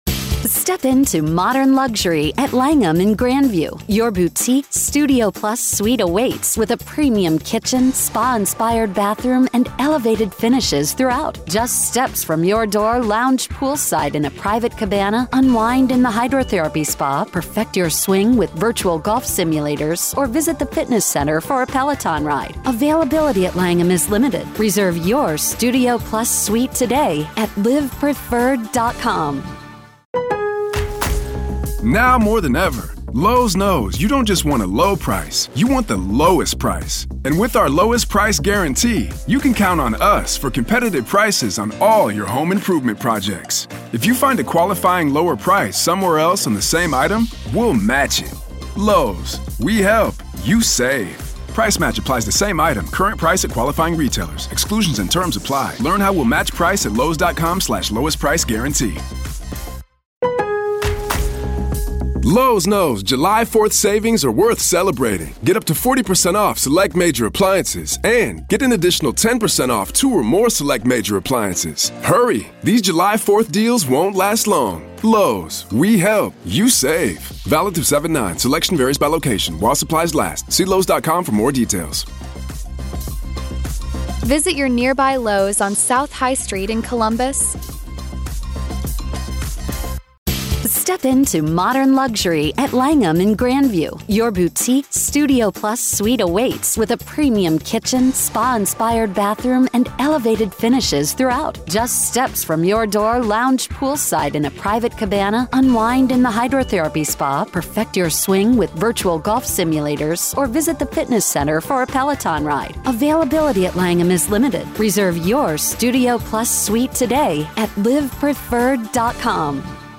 The conversation highlights how Daybell's trial mirrors the earlier trial of Lori Vallow, with strong evidence pointing to his involvement in multiple murders, including that of his previous wife, Tammy Daybell.